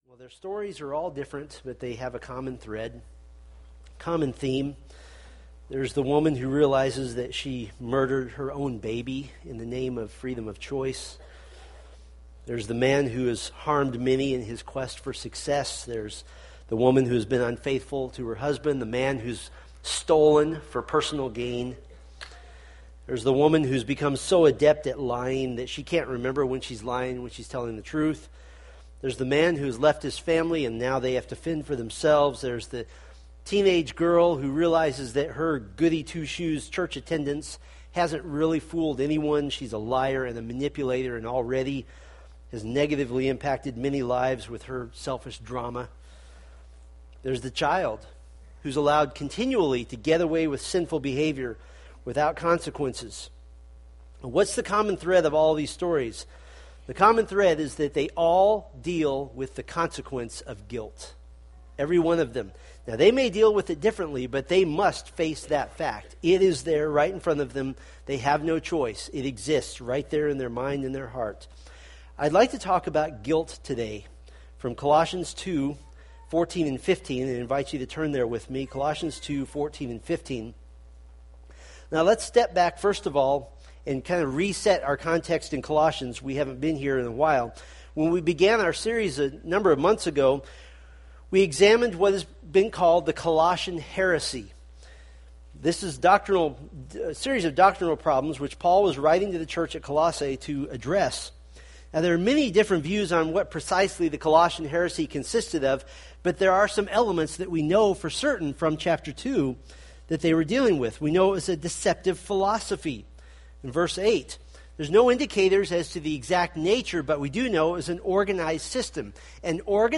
Colossians Sermon Series